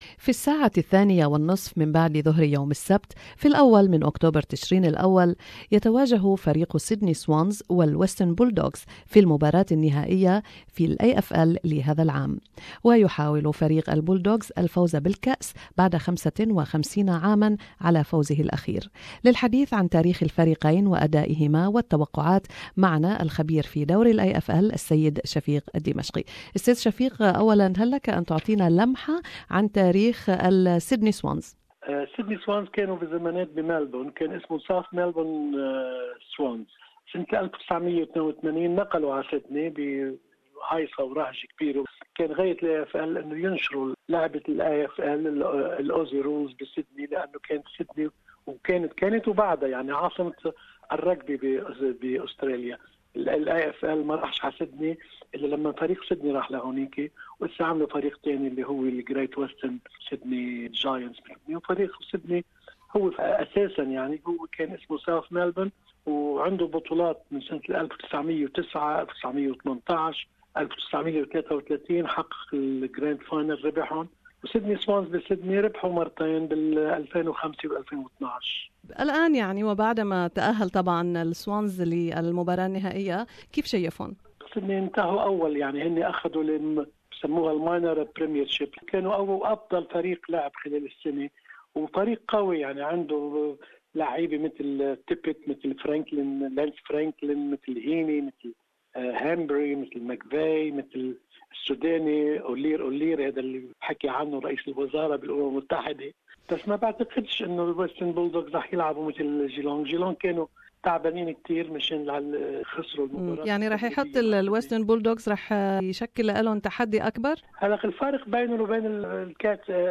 AFL finals analysis with sport commentator